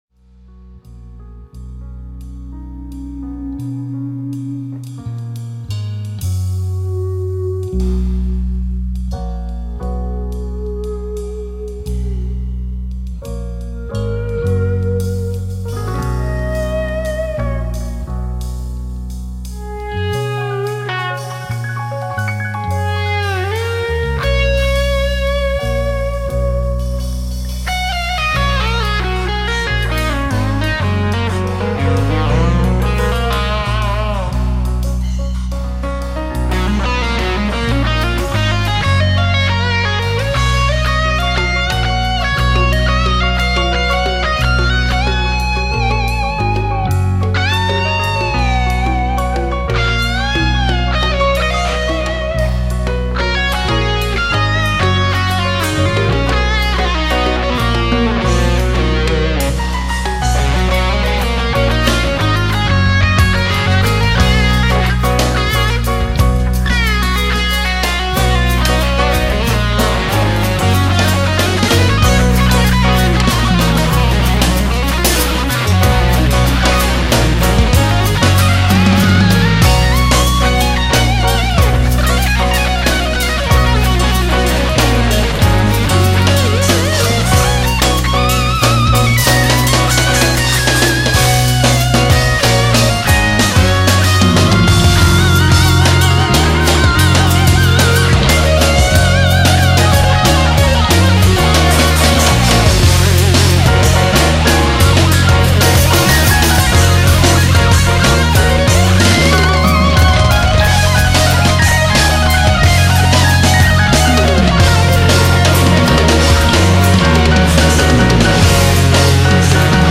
Jazz Metalt tud nekem ajánlani valaki?